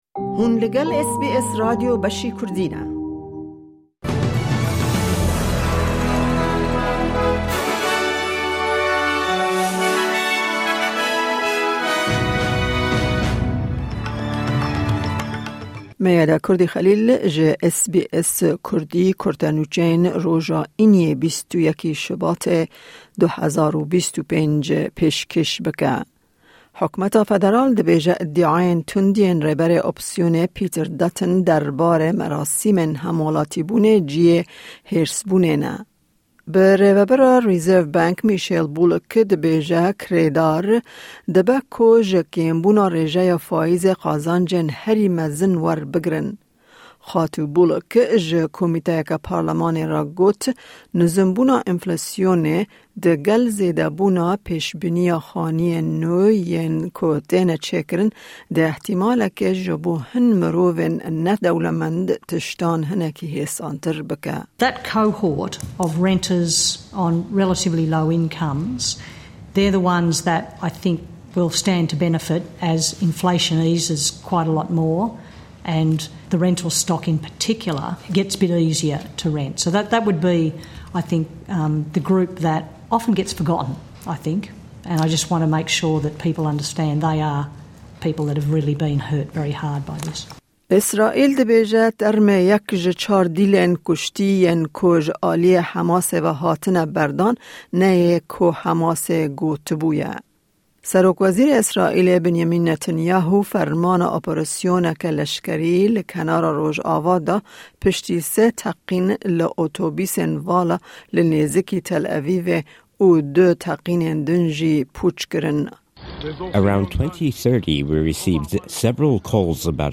Kurte Nûçeyên roja Înî 21î Şibata 2025